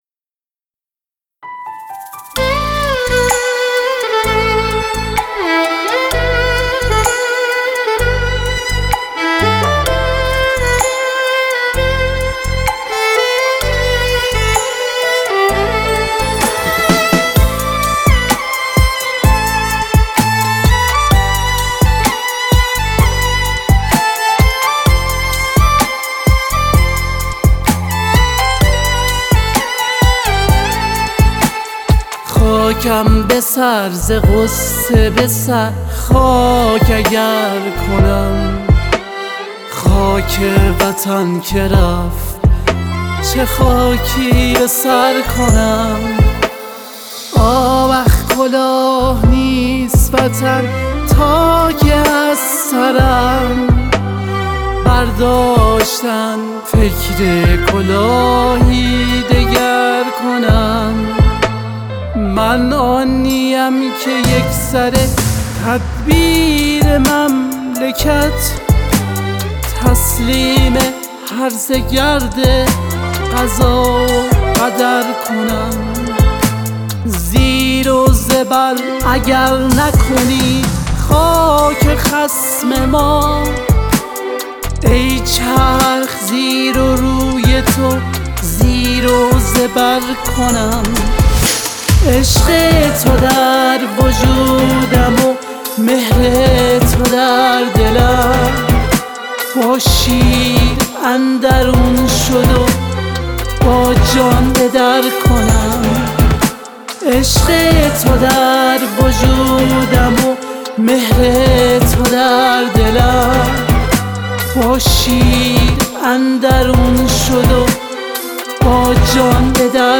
پاپ
آهنگ با صدای زن
آهنگ غمگین آهنگ محلی